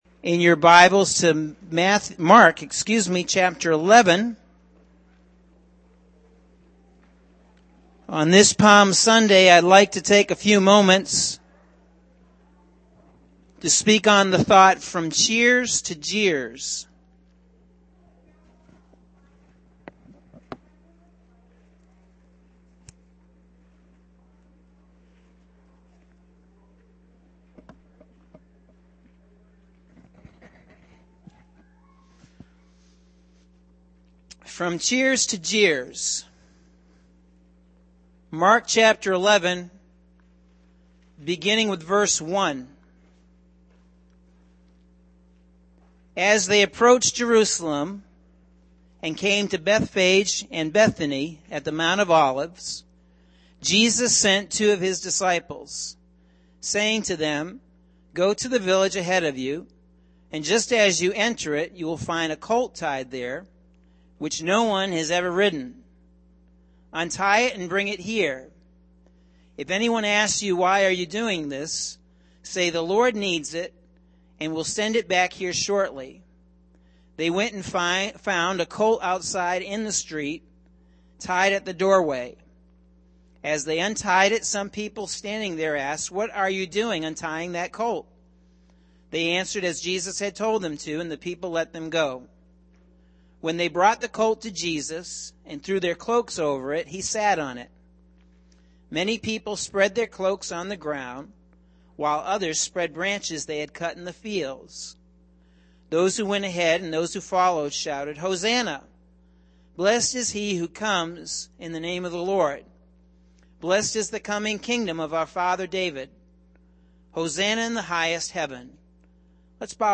Sunday April 17th – AM Sermon – Norwich Assembly of God